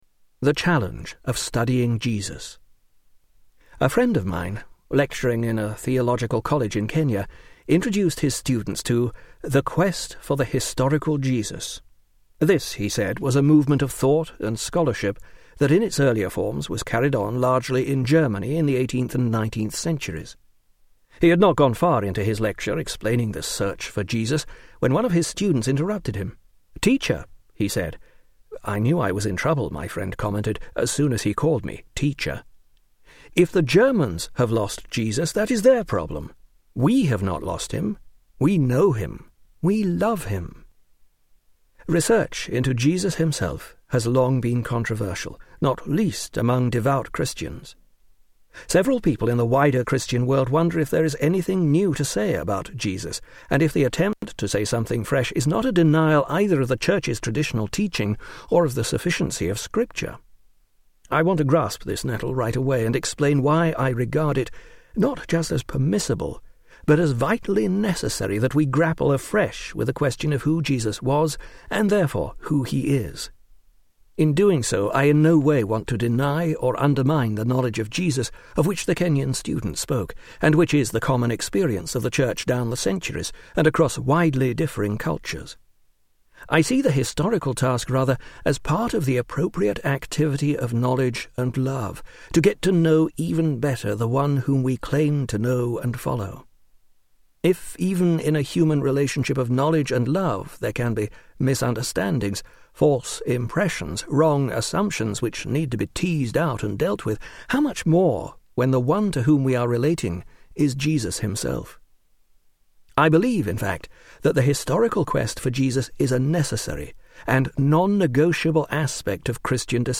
Tags: Christian Books Audio books Christian Audio books Media